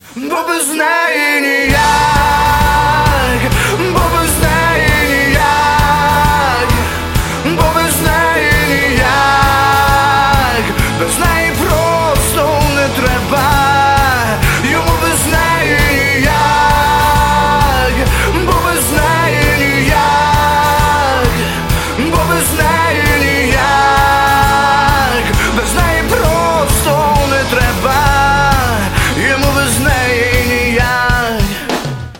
• Качество: 192, Stereo
украинский рок